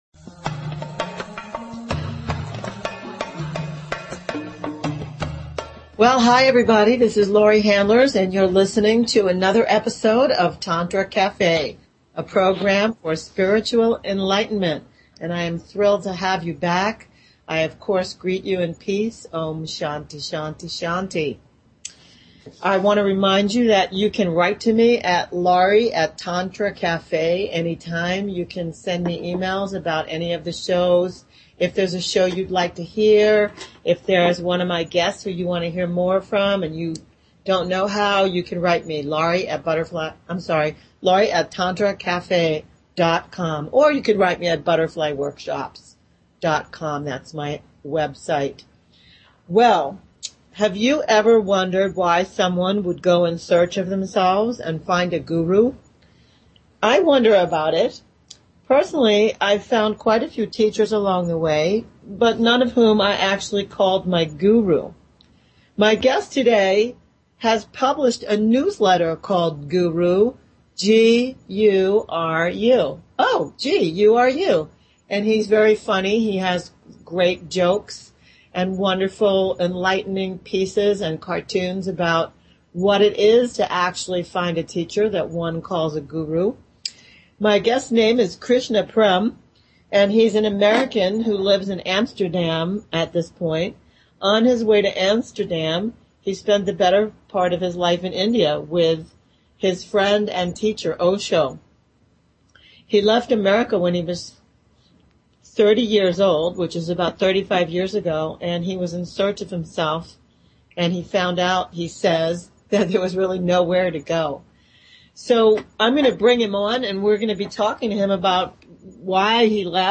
Talk Show Episode, Audio Podcast, Tantra_Cafe and Courtesy of BBS Radio on , show guests , about , categorized as